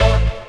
orgTTE54002organ-A.wav